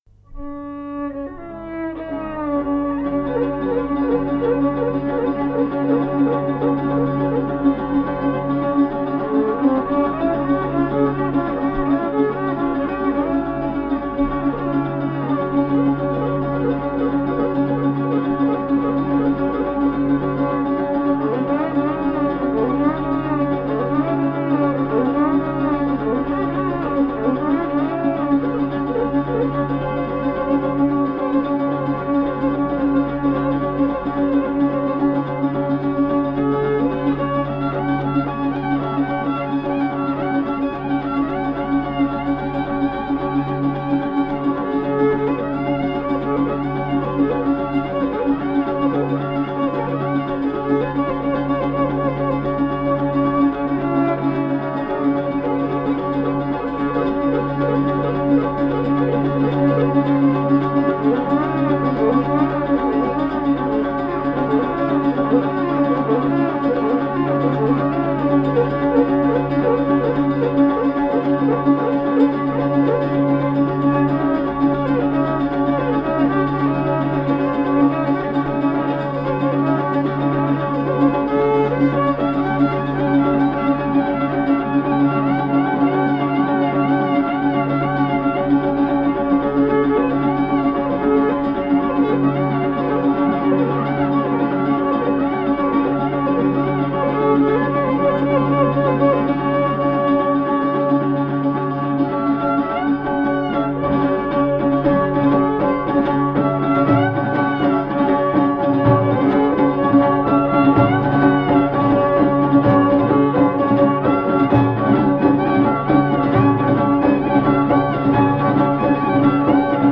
Kaba e llojit me dy pjesë: “e qarë” dhe valle e gëzuar. Pjesa e parë përshfaqet si kaba labiko ose me motive labe. Mbështetet mbi motive vajtimore aq të ngjashme por dhe të veҫanta në raport me kabatë e tjera për violinë.
Në pjesën e parë, violina si marrëse shoqërohet vetëm nga isoja e instrumenteve kordofone si llahuta dhe bakllamaja, pa patur prerje. Pjesa e dytë shfaqet si valle e gëzuar, mbi një motiv të njohur edhe si “Vallja e Delvinës”.